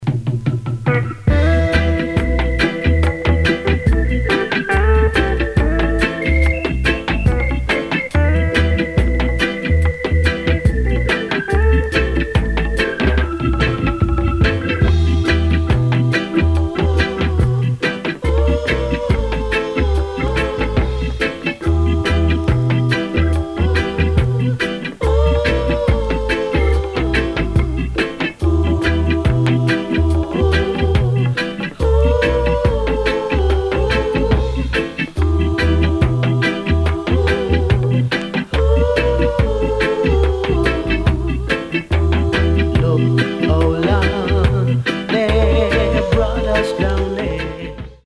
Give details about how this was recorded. Recorded: Joe Gibbs 'N' Harry J. Studios Kgn. Ja.